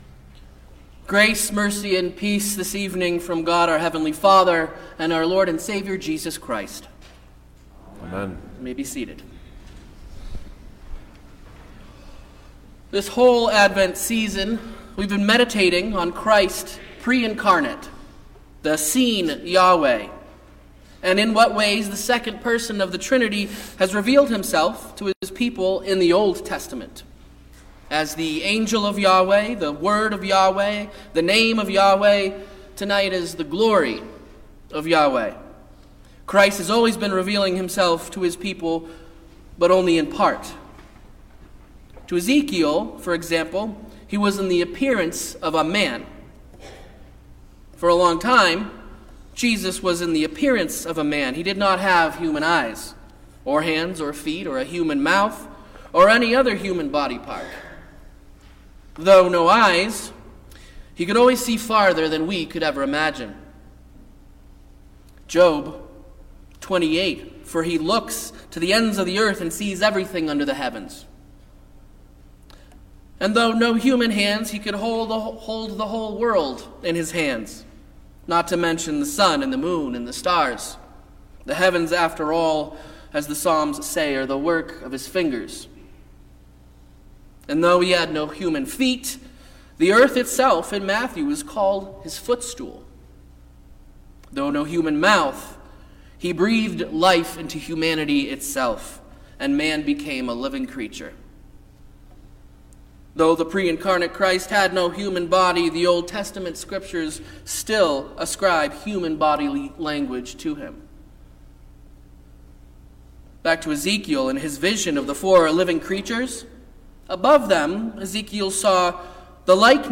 Christmas Eve Midnight